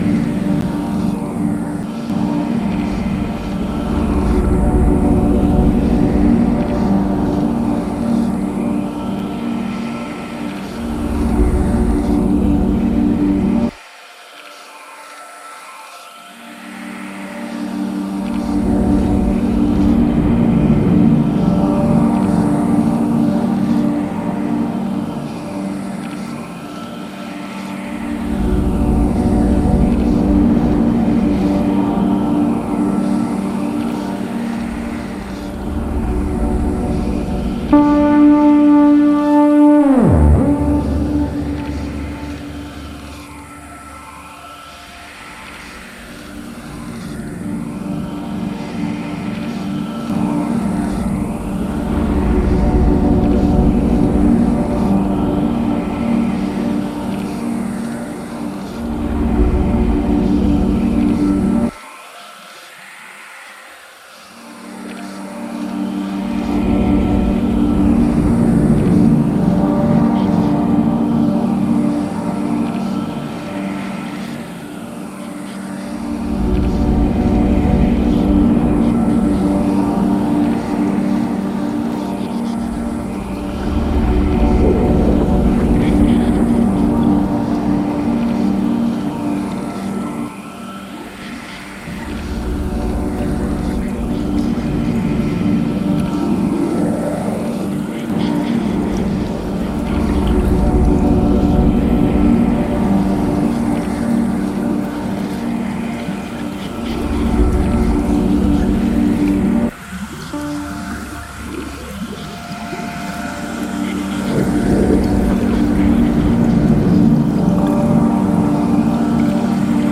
House Ambient